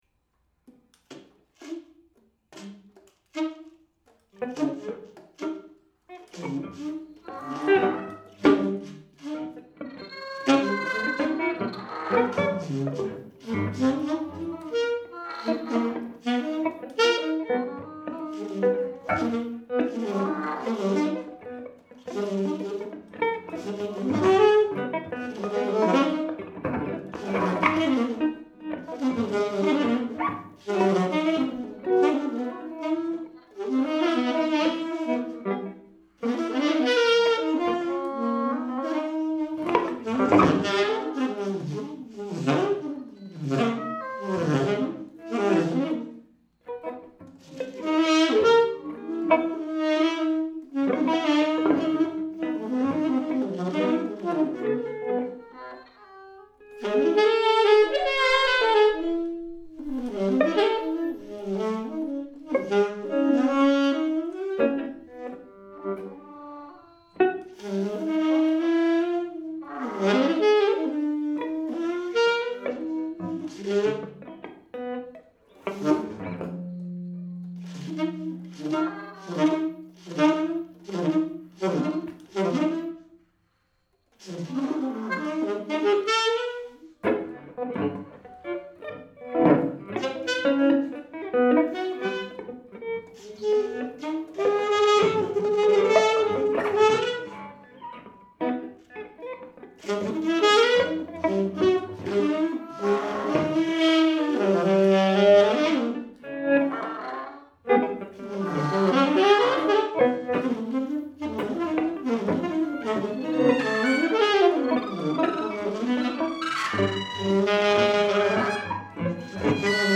saxophone
guitar